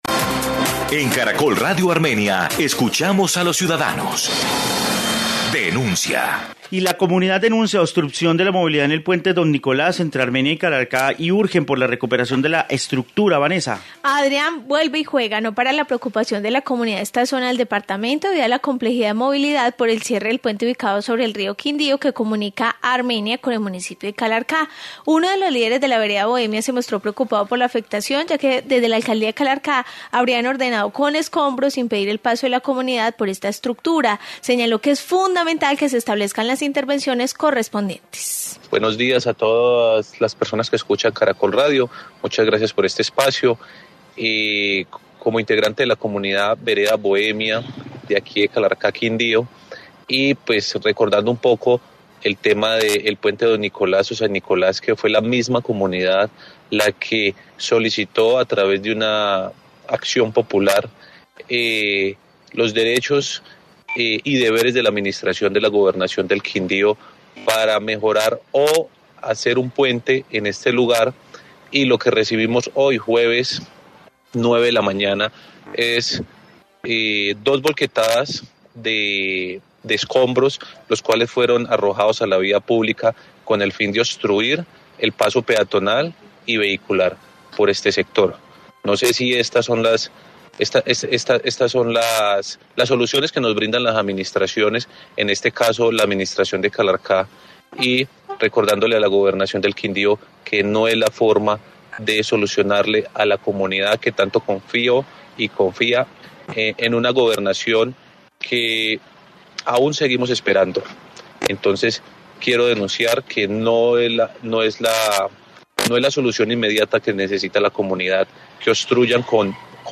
Informe puente Don Nicolás